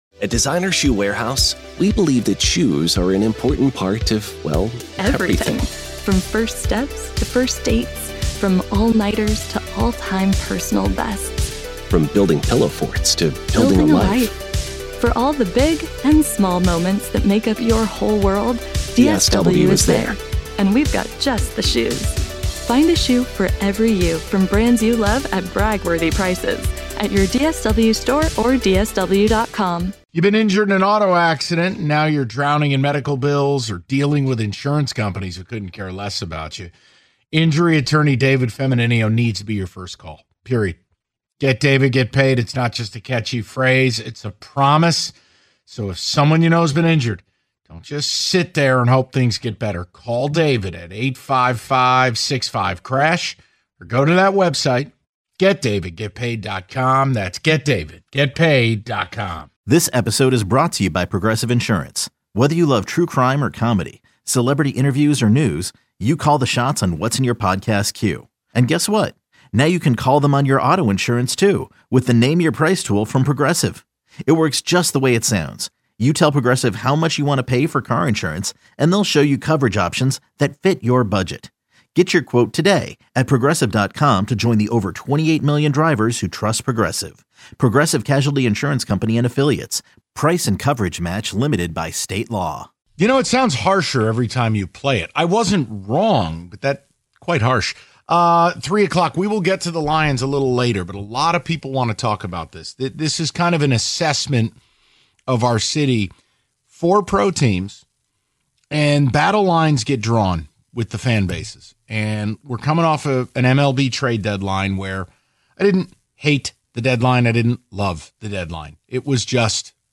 take a few more of your calls